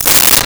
Keys Drop
Keys Drop.wav